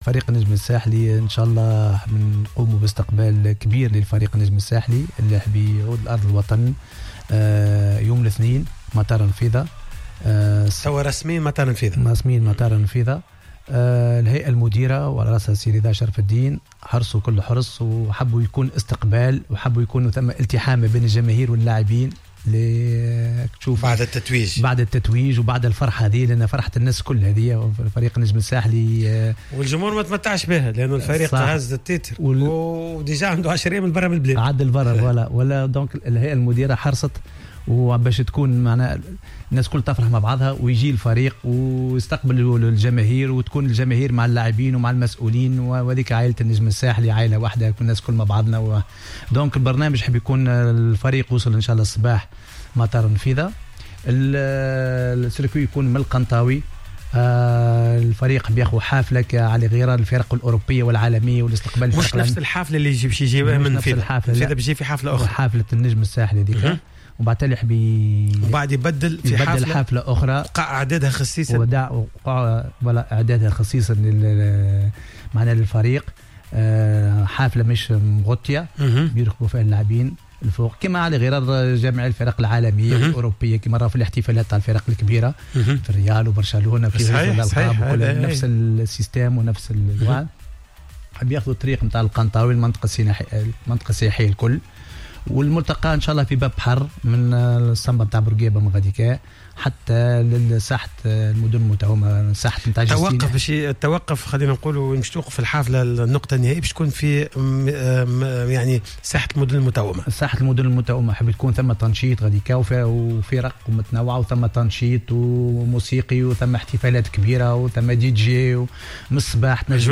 مداخلة في حصة "Planète Sport"